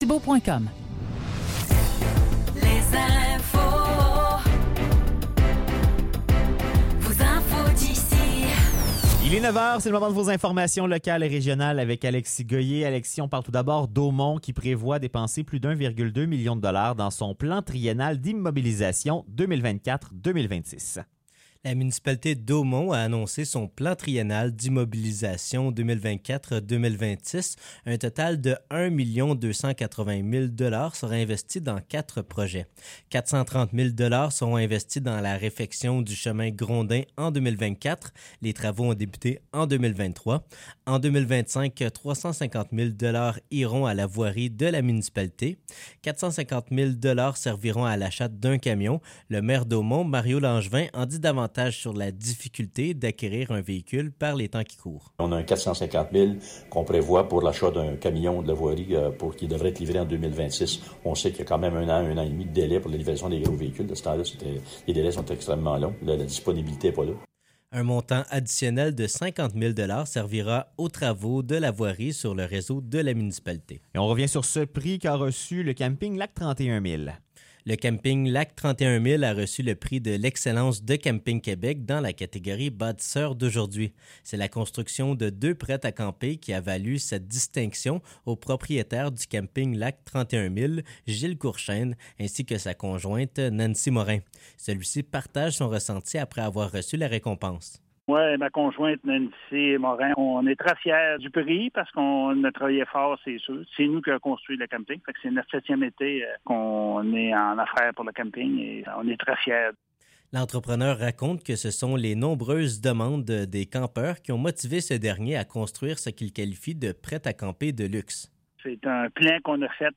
Nouvelles locales - 28 décembre 2023 - 9 h